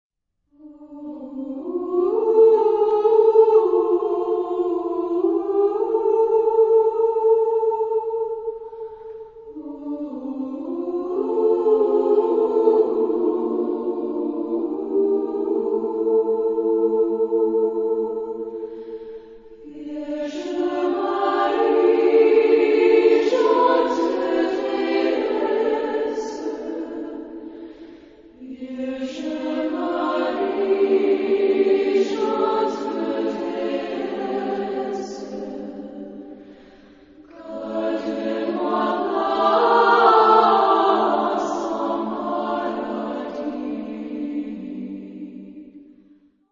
Genre-Stil-Form: Gebet ; geistlich
Charakter des Stückes: religiös
Chorgattung: SSA  (3 Frauenchor Stimmen )